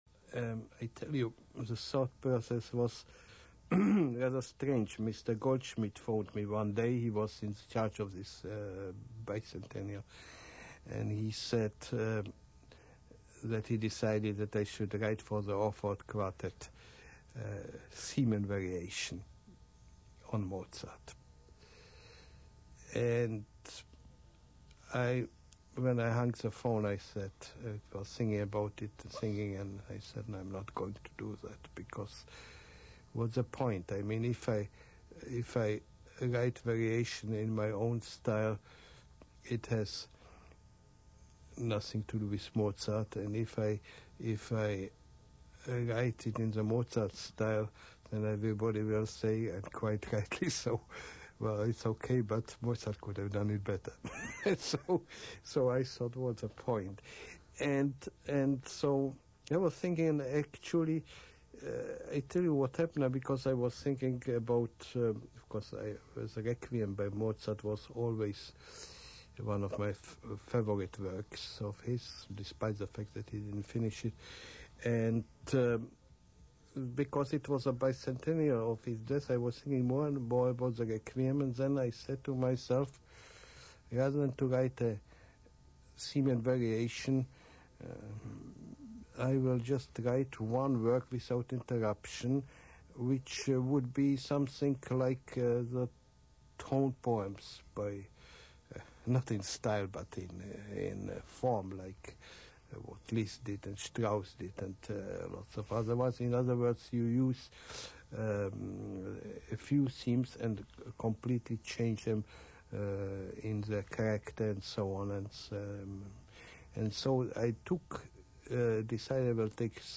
On January 17, 1992, CBC Stereo presented the Orford Quartet's Full Glory of Mozart program, which included the premi�re of Morawetz' Tribute to Mozart quartet.
Morawetz describes the circumstances and inspiration for his fifth quartet.